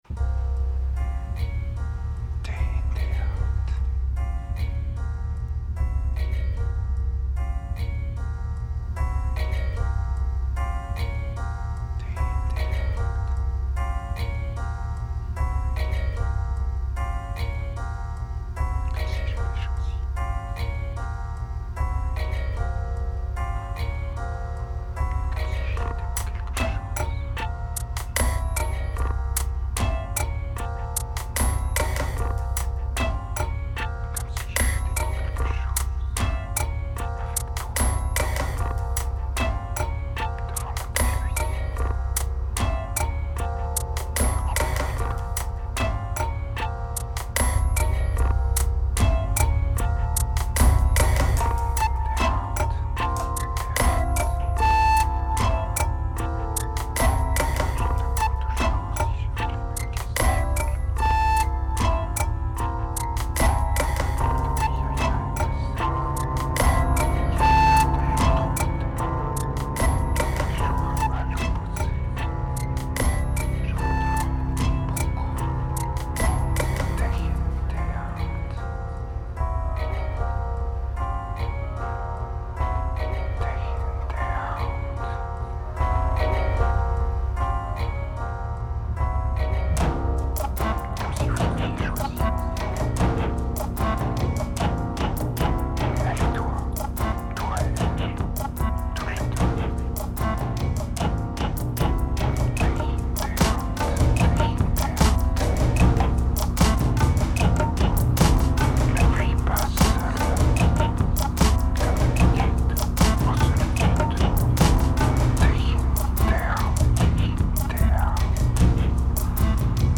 Vocals are still poor but a bit more directed.